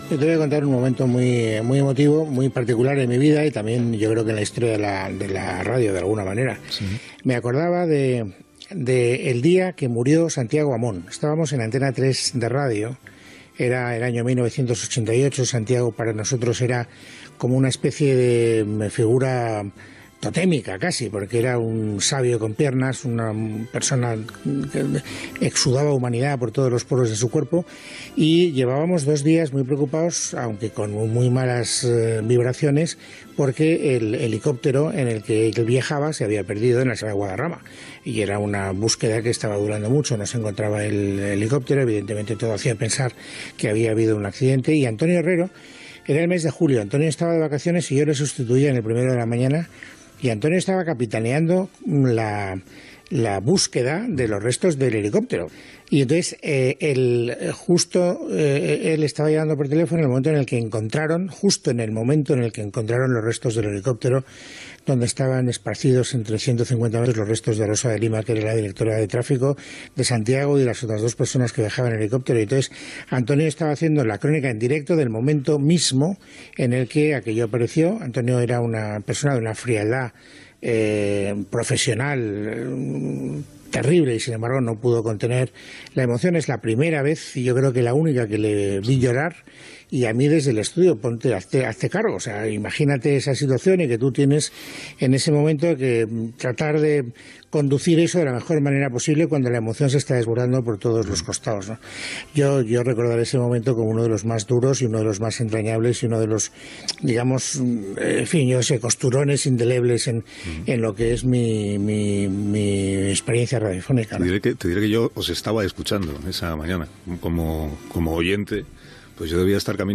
Info-entreteniment
FM